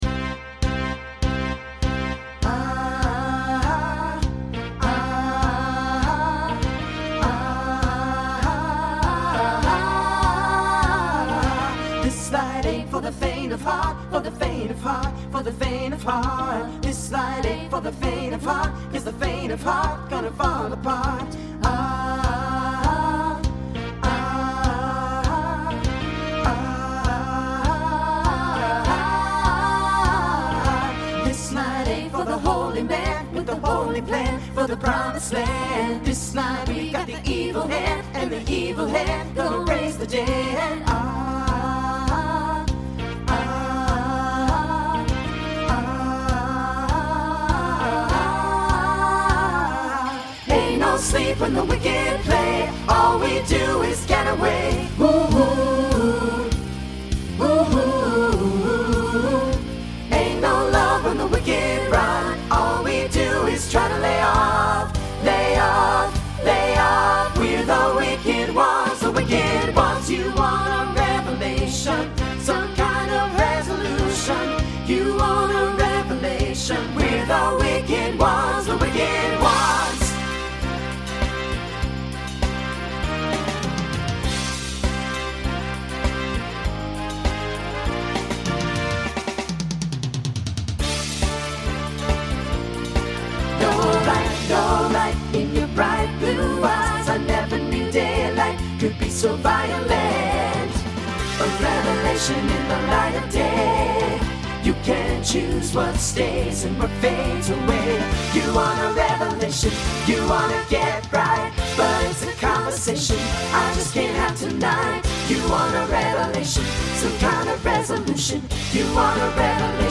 New SSA voicing for 2025.
SSA Instrumental combo Genre Pop/Dance
Mid-tempo